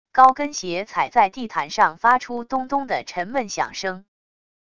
高跟鞋踩在地毯上发出咚咚的沉闷响声wav音频